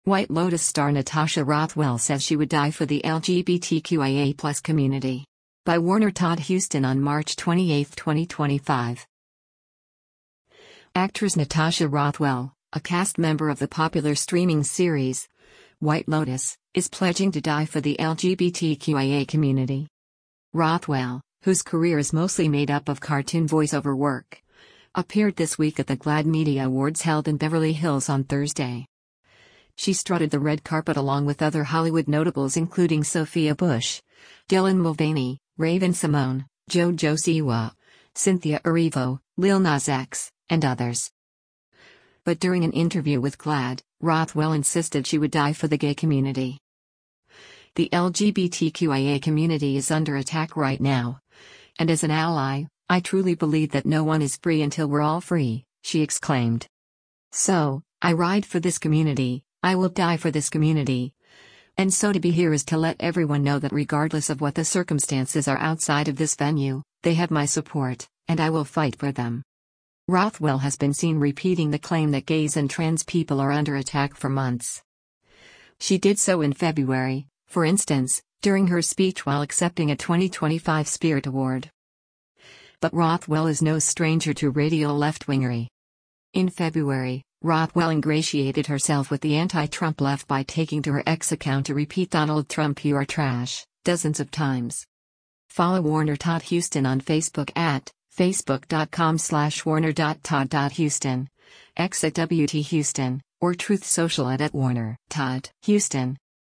Rothwell, whose career is mostly made up of cartoon voice over work, appeared this week at the GLAAD Media Awards held in Beverly Hills on Thursday.
But during an interview with GLADD, Rothwell insisted she would “die” for the gay community.